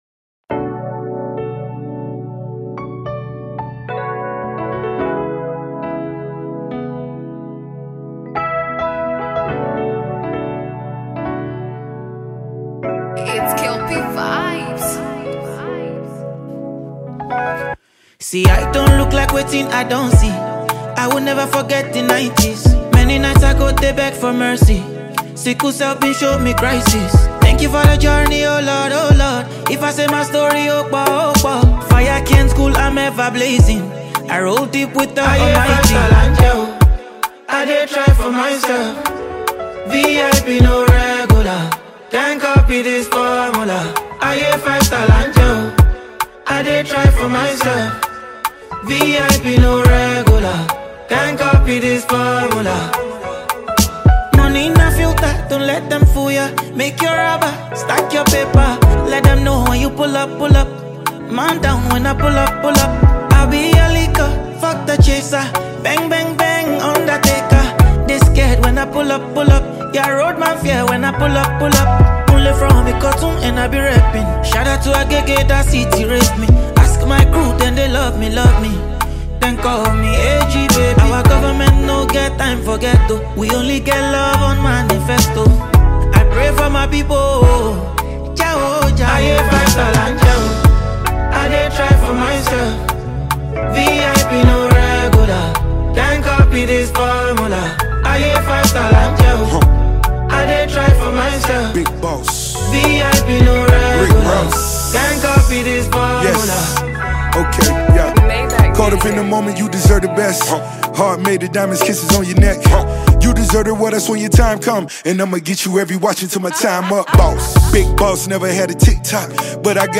Afro Urban singer and songwriter